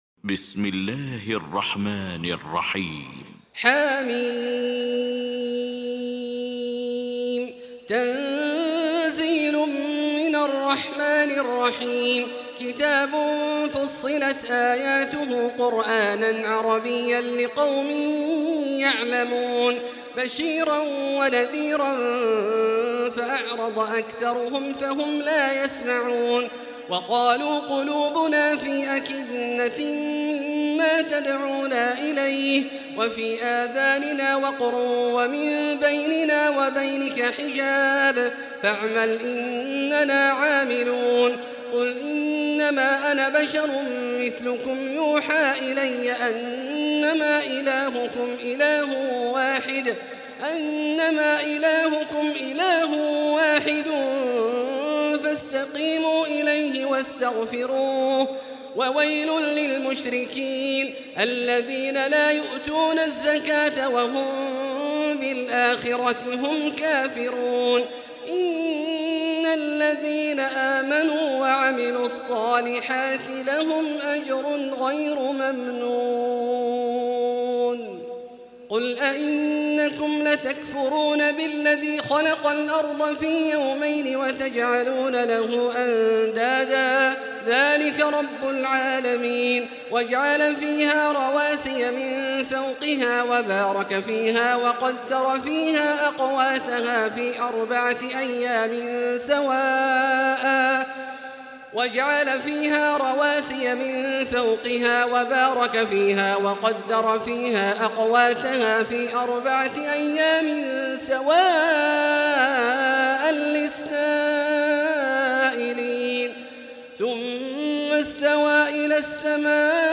المكان: المسجد الحرام الشيخ: فضيلة الشيخ عبدالله الجهني فضيلة الشيخ عبدالله الجهني فضيلة الشيخ ياسر الدوسري فصلت